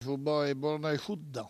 Langue Maraîchin
Patois
Catégorie Locution